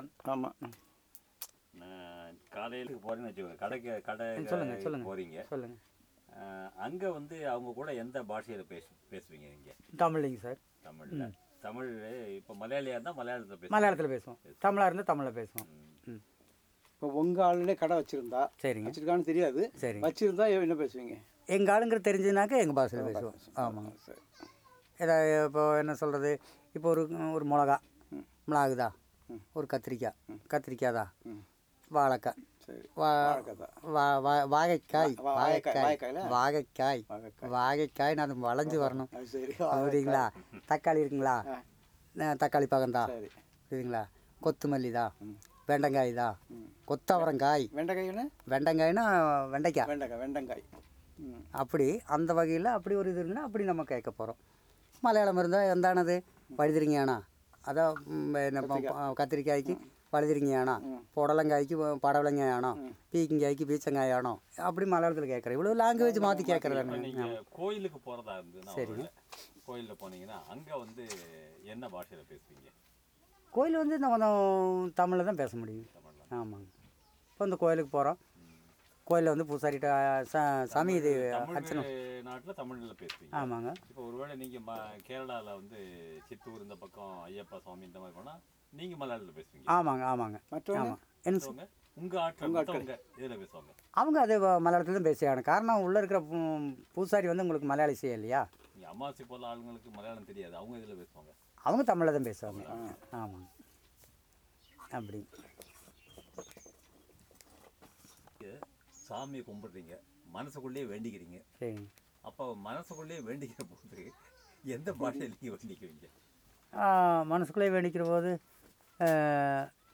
Conversation about locations of language use